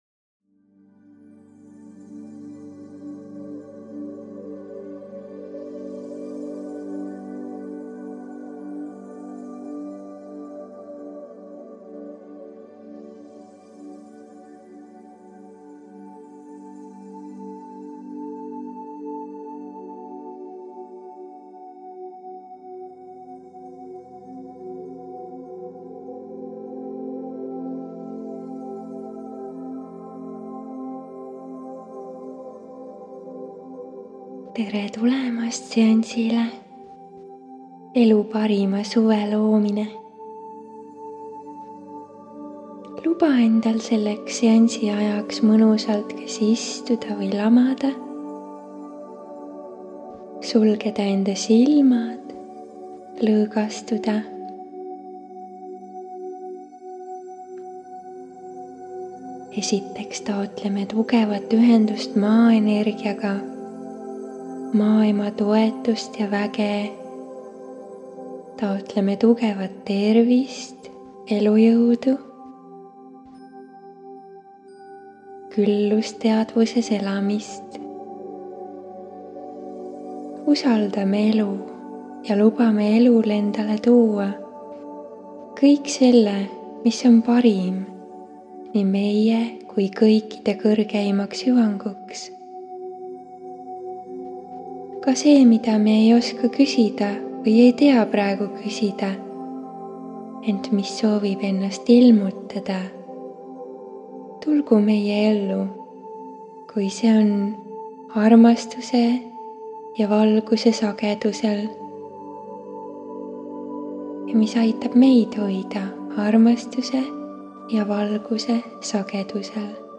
SIIT LEHELT SAAD KUULATA: MEDITATSIOONI ELU PARIM SUVI - UNISTUSTE TÄITUMISE KIIRENDUS Suvise pööripäeva ajal kuulamiseks 18 -23. juunini salvestatud aastal 2020 *** See meditatsioon on spetsiaalselt selleks loodud, et väestada sinu südamesoovide täitumist, seda kiirendada ja luua sulle tugev energeetiline kaitse ja tugevus, et oleksid suuteline seda õnne enda ellu vastu võtma.